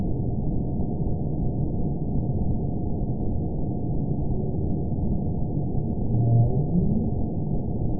event 914079 date 04/27/22 time 05:01:10 GMT (3 years ago) score 8.79 location TSS-AB01 detected by nrw target species NRW annotations +NRW Spectrogram: Frequency (kHz) vs. Time (s) audio not available .wav